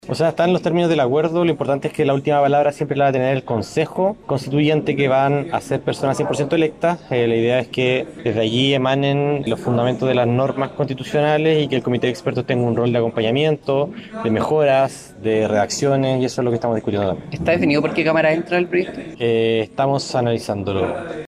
El presidente de Convergencia Social, Diego Ibáñez, dijo que la preferencia la tendrán los consejeros electos.